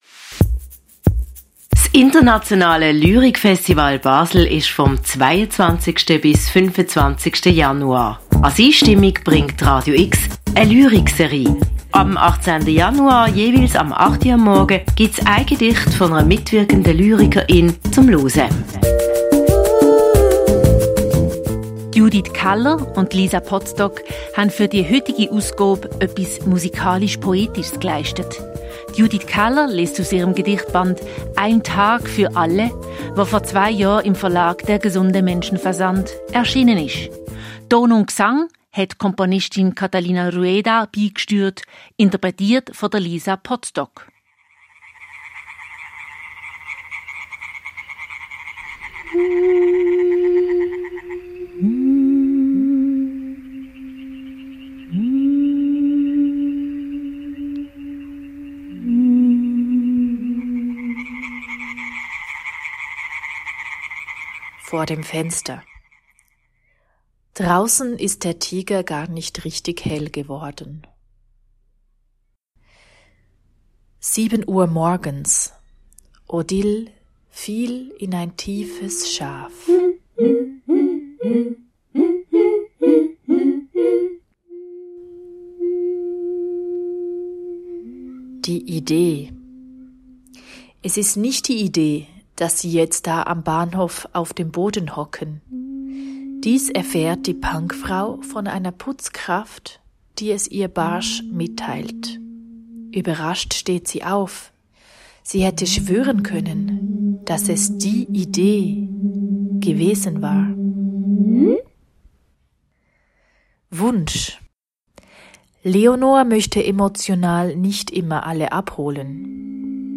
Basler Lyrikfestivals vom 18. bis 25. Januar jeweils um 8.00 Uhr